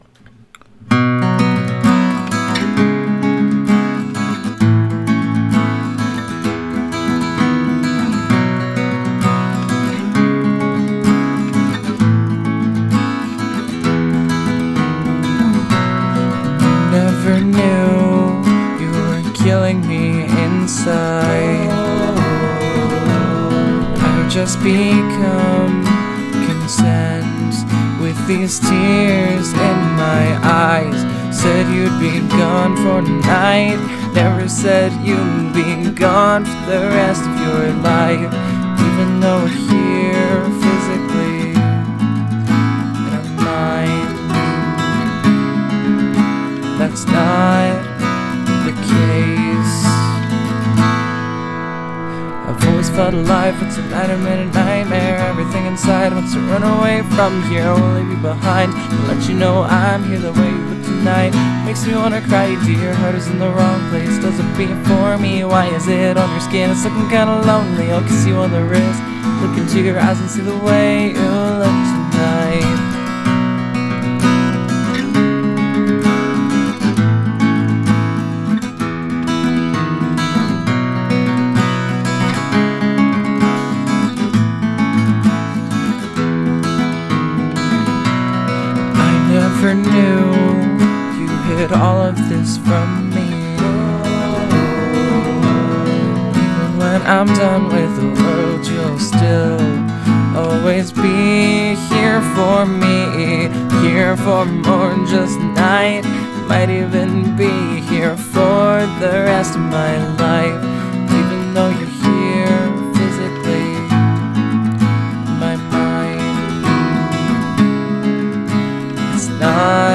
This is my acoustic song that I have worked on for the past three hours (Its 3AM).
It is my first official acoustic song and you should say whats wrong.